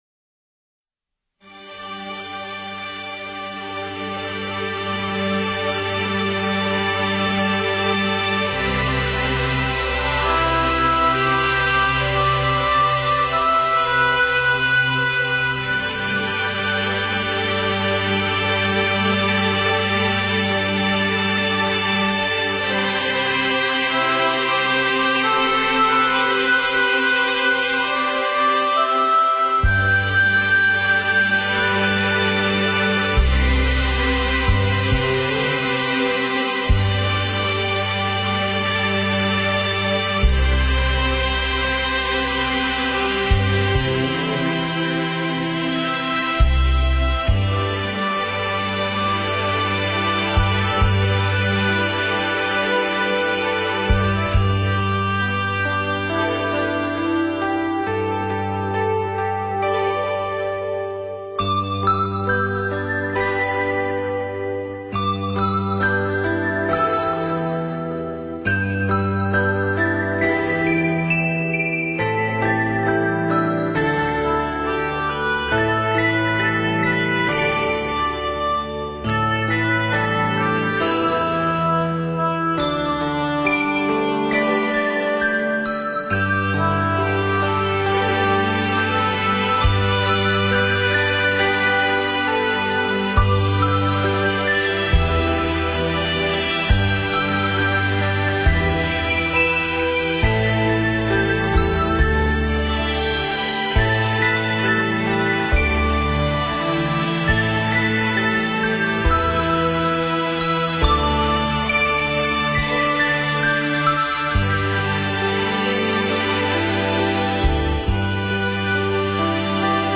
佛音 诵经 佛教音乐 返回列表 上一篇： 祈愿(点灯 文